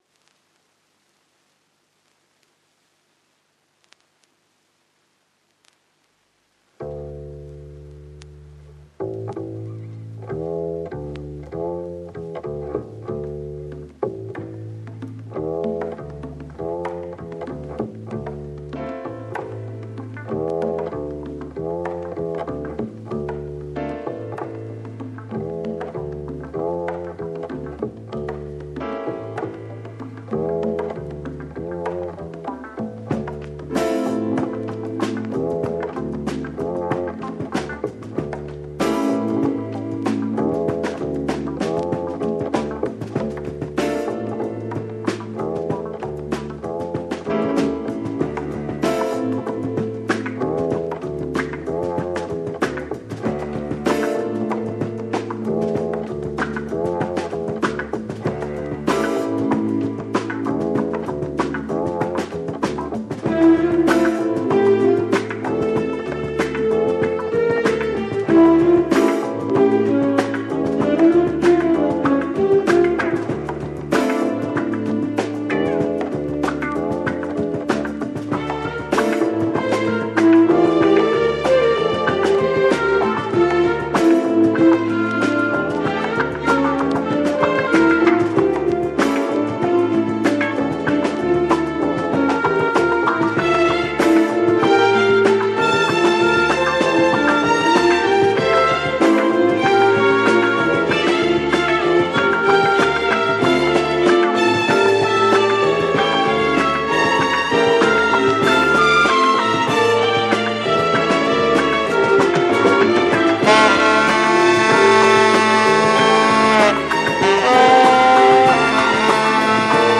Movie Soundtrack album Groove cinematic Jazz